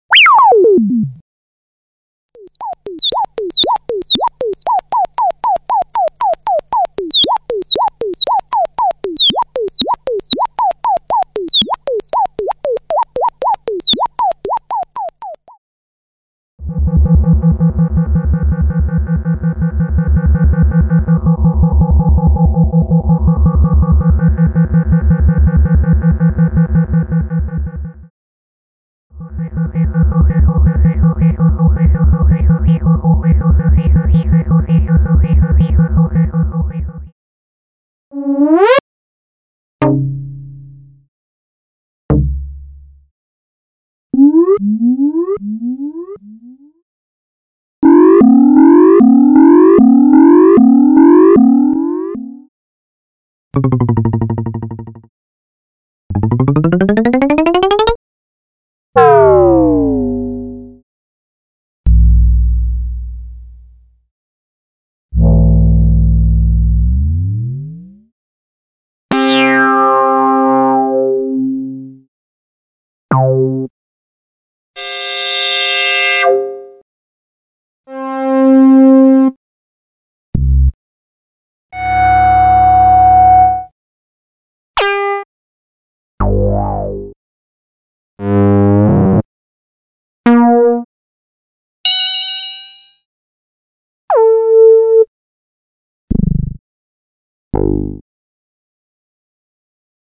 Elektronische Musik und Studio nah Frankfurt.